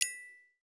Quiet BellOctave Notification.wav